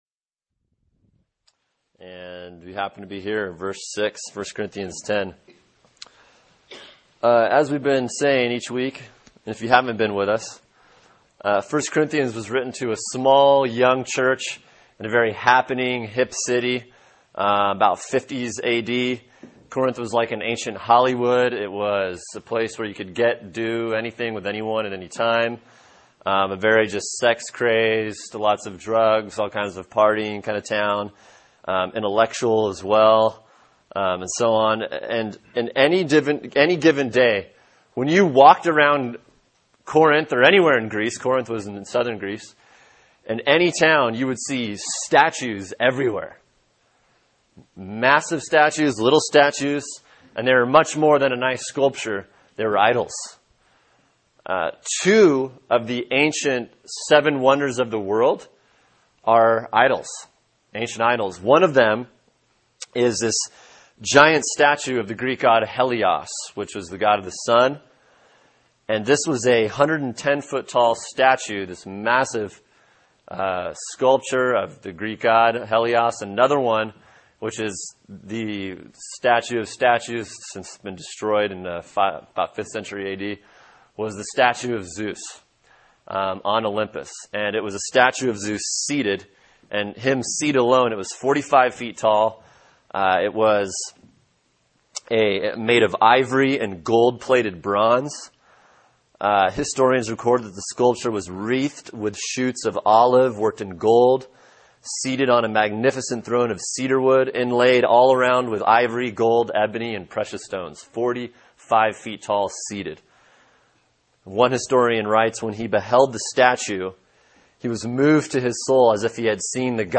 Sermon: 1 Corinthians 10:6-13 “Idolatry” | Cornerstone Church - Jackson Hole